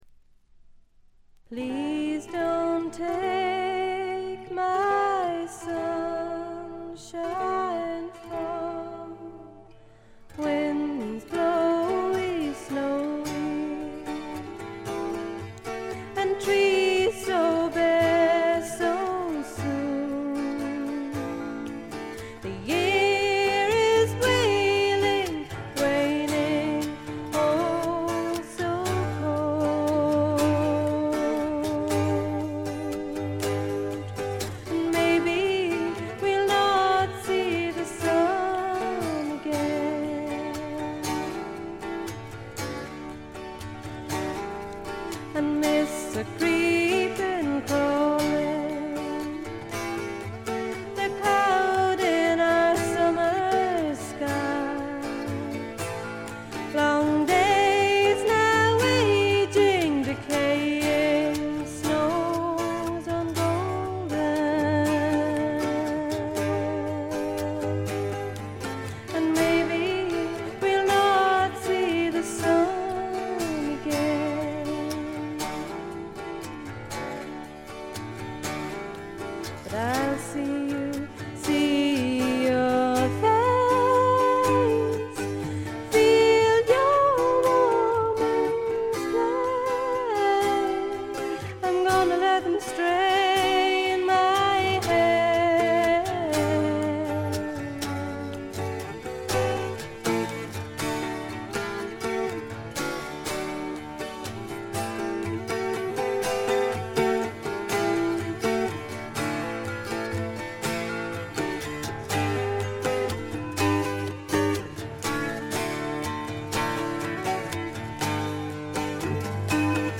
ほとんどノイズ感無し。
ひとことで言って上品で風格のあるフォーク･ロックです。
試聴曲は現品からの取り込み音源です。
Recorded At Marquee Studios